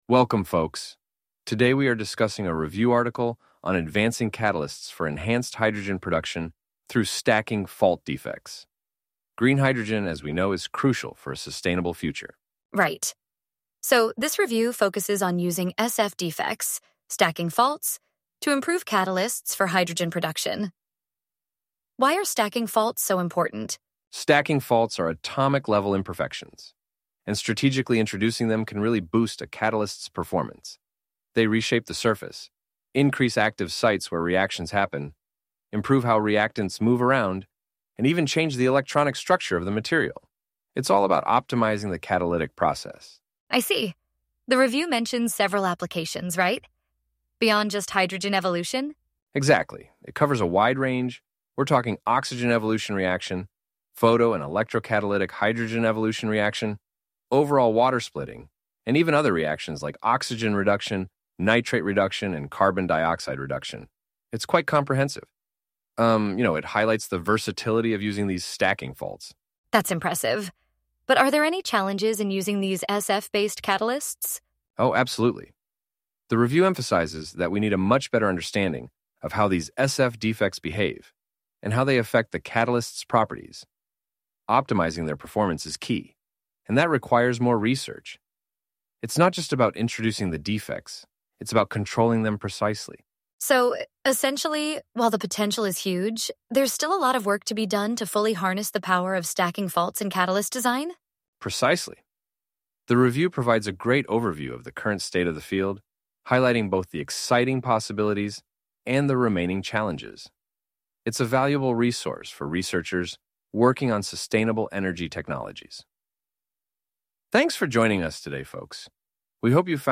• 클릭하면 논문의 길이에 따라 수십 초에서 수 분 정도 후에 남녀 한 명씩이 논문 내용을 설명해주는 podcast가 재생됩니다.
• 개인적인 느낌으로 Google NotebookLM에서 제공하는 Audio Overview에 비해 생동감이 덜해서 아쉽습니다.